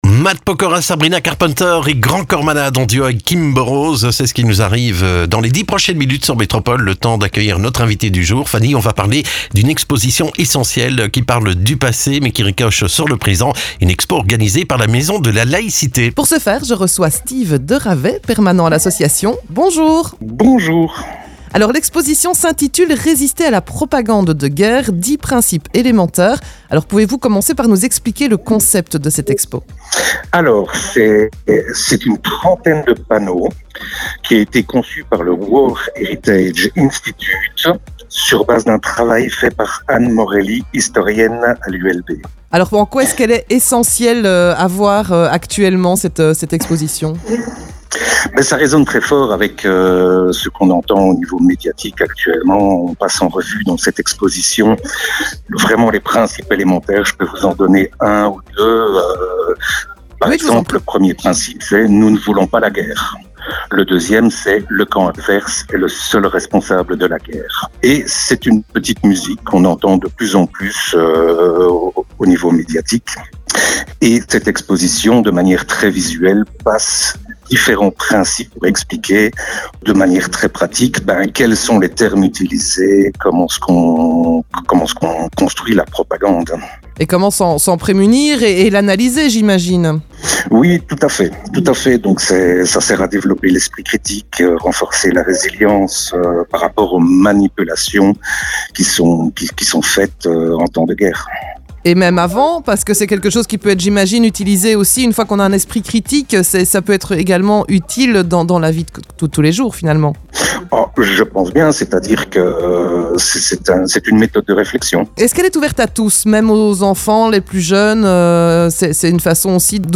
Le coup de fil